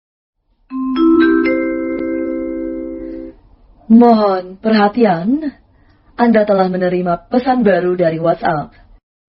Kategori: Nada dering
Dengan nada yang jelas dan formal, kamu nggak bakal lagi kelewatan pesan penting.